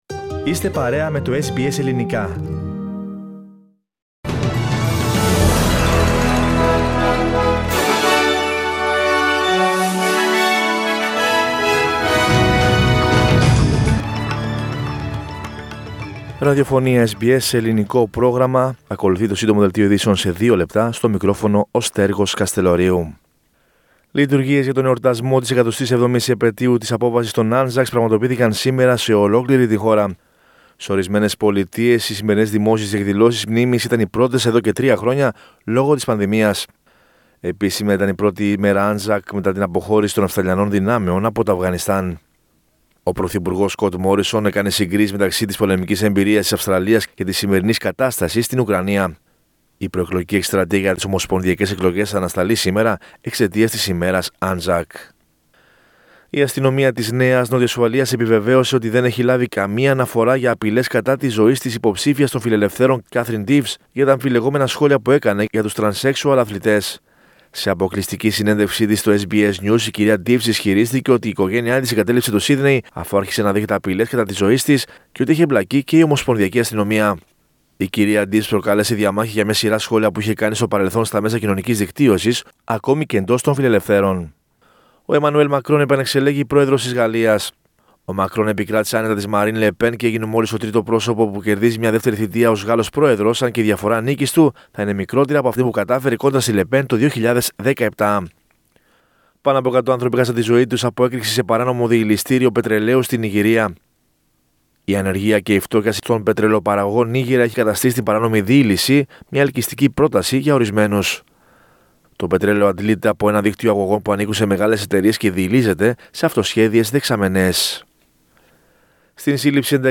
Greek News Flash.